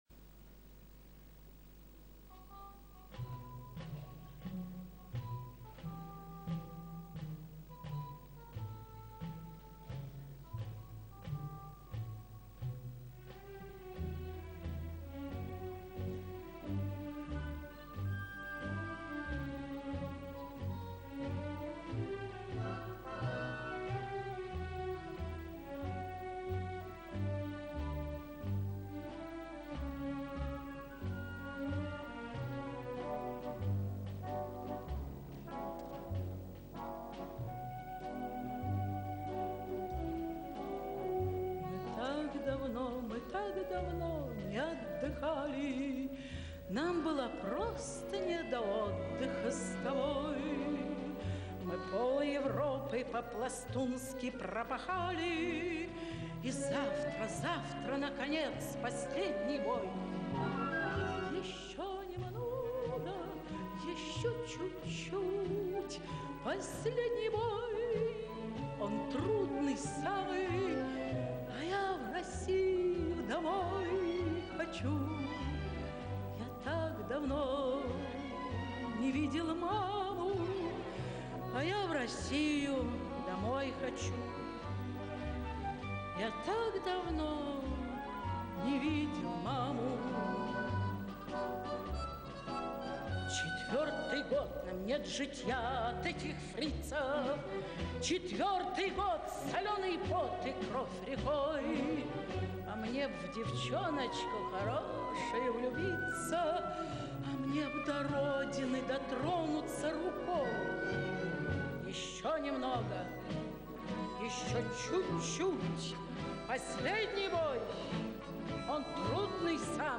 Источник с видеозаписи концерта певицы